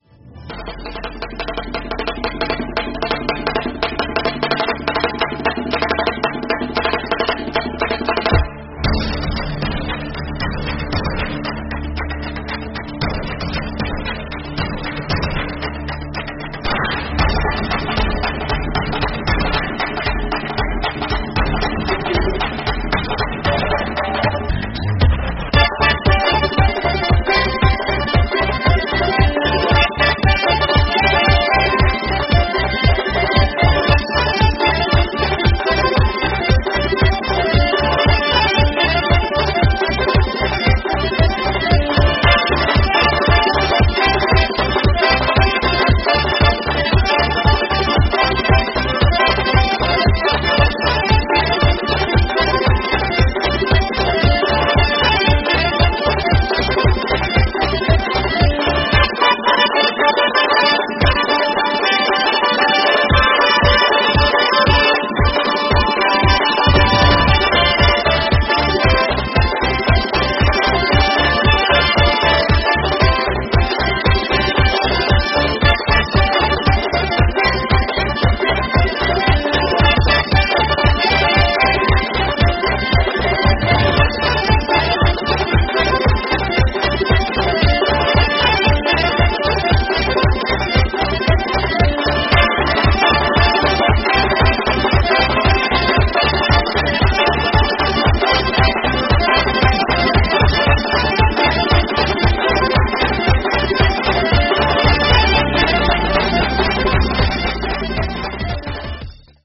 Звук лезгинки Красивая чеченская лезгинка минус